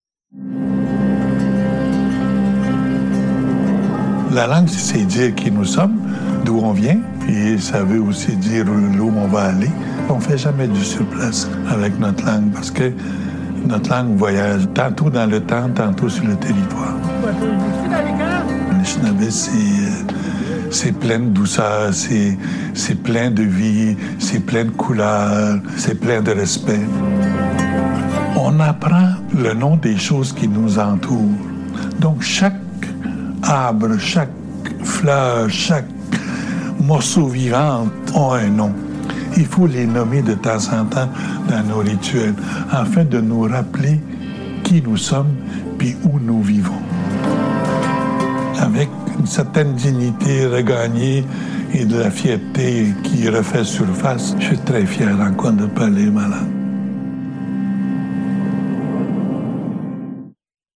Publicité pour la journée des langues autochtones